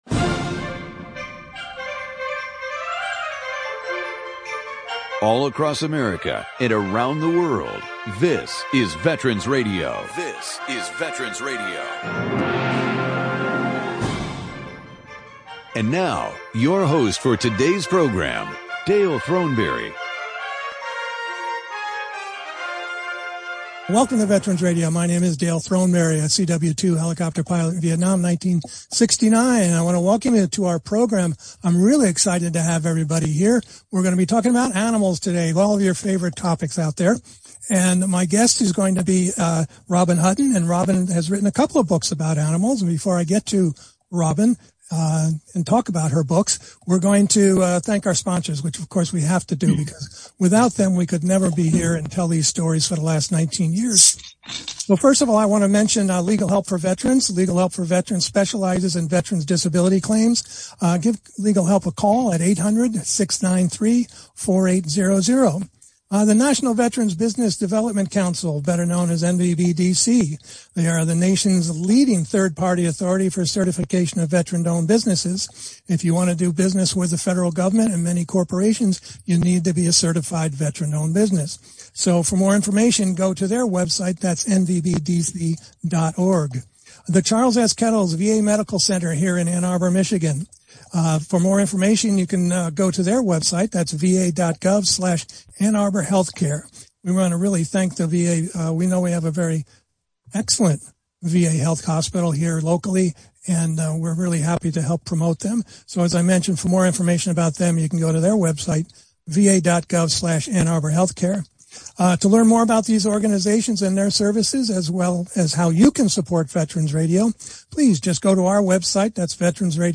Listen to her fascinating stories of war horses, war dogs, and more!